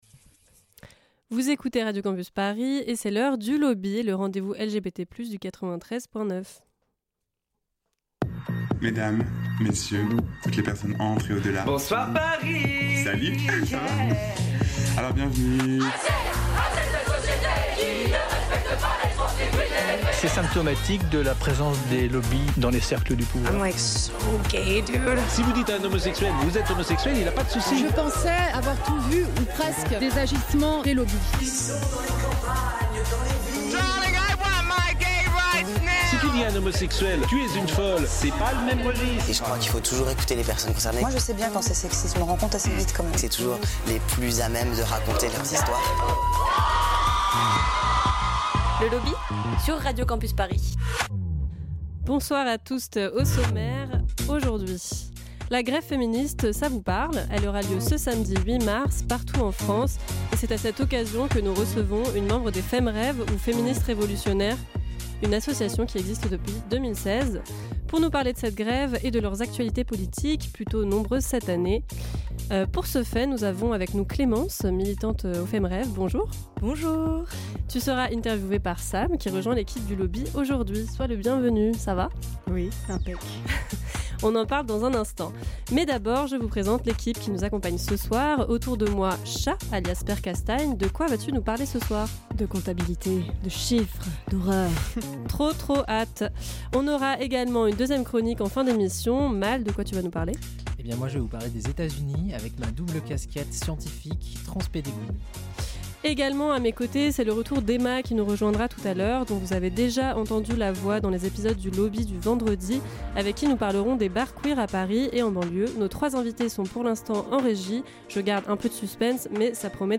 On en parle pendant trente minutes avec nos trois invitées.